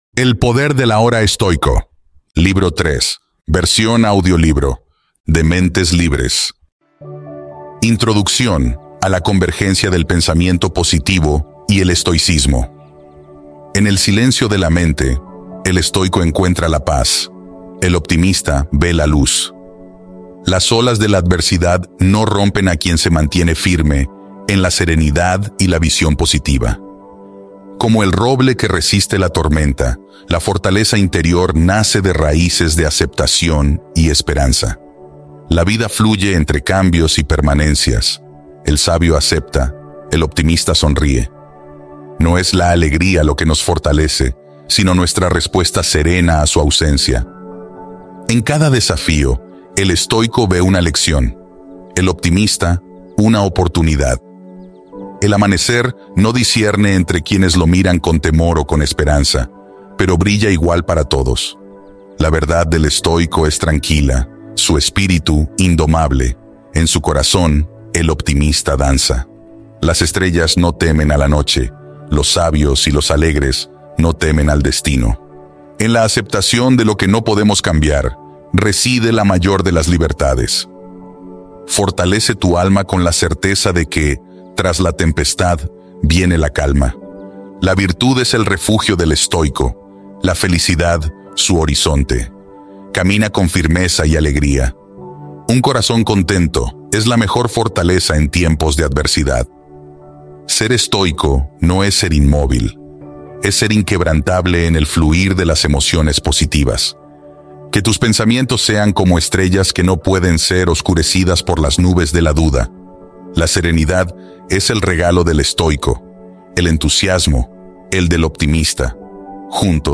AUDIOLIBRO 3: EL PODER DEL AHORA ESTOICO
DEMO-AUDIOLIBRO-3-EL-PODER-DEL-AHORA-ESTOICO.mp3